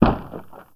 DEMOLISH_Short_02_mono.wav